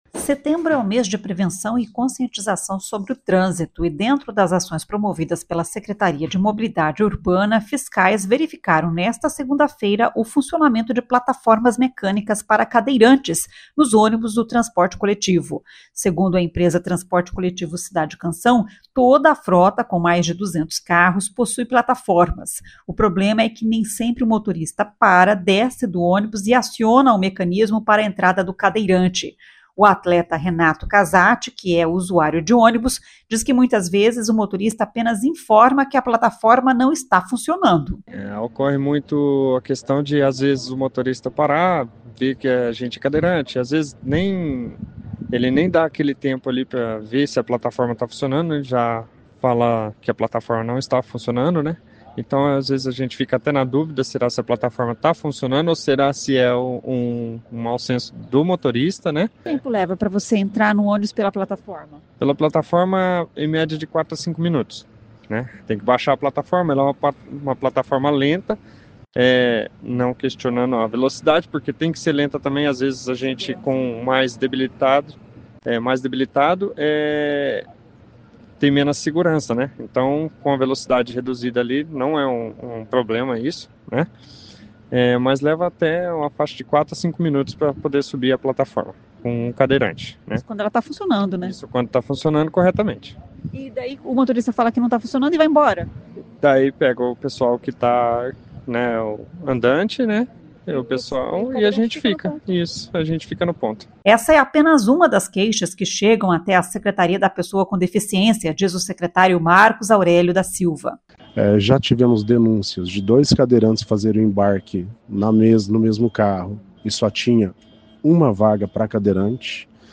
Essa é apenas uma das queixas que chegam até a Secretaria da Pessoa com Deficiência, diz o secretário Marcos Aurélio da Silva.
O secretário de Mobilidade Urbana Luciano Brito diz que o objetivo é conhecer as linhas mais usadas por pessoas com deficiência para intensificar a fiscalização.